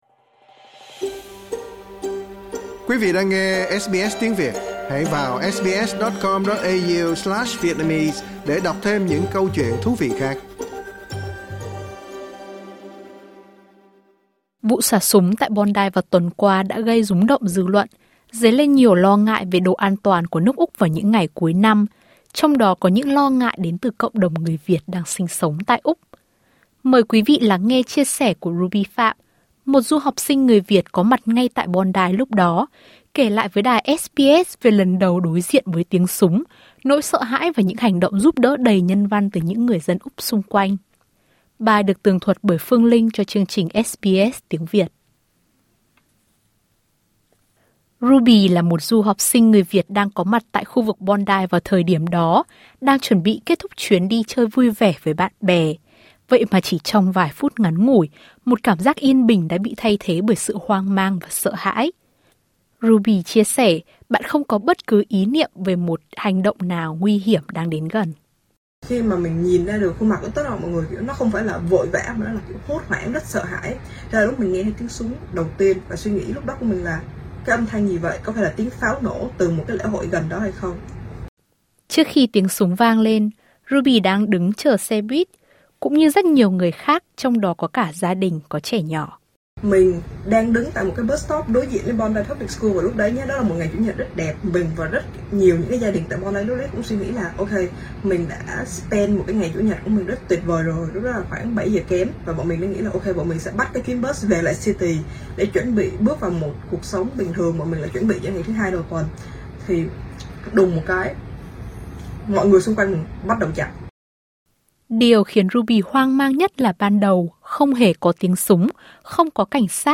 một du học sinh người Việt có mặt ngay tại Bondi lúc đó, kể lại với đài SBS về lần đầu đối diện với tiếng súng, nỗi sợ hãi và những hành động giúp đỡ đầy nhân văn từ những người dân Úc xung quanh.